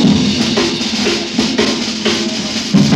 JAZZ BREAK 7.wav